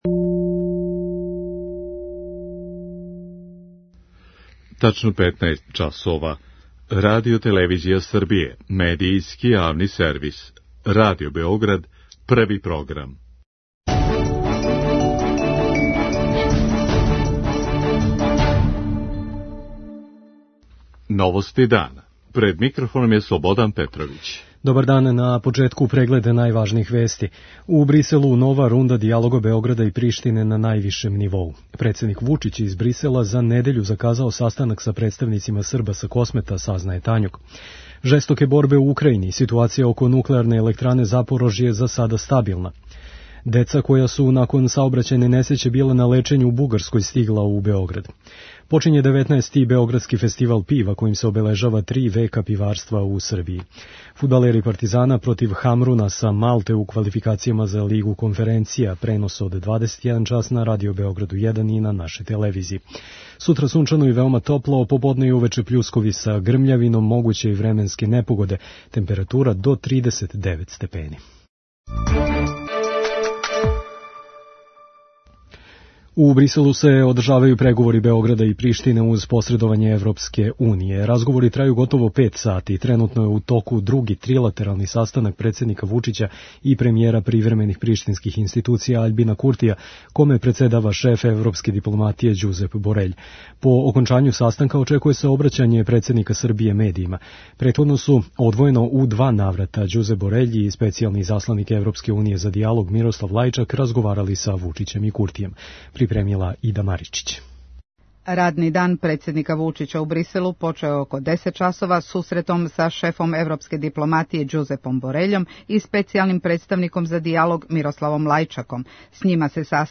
У зграду Европске службе за спољне послове у Бриселу где се одржава дијалог, стигао је и амерички изасланик за Западни Балкан Габријел Ескобар. преузми : 5.27 MB Новости дана Autor: Радио Београд 1 “Новости дана”, централна информативна емисија Првог програма Радио Београда емитује се од јесени 1958. године.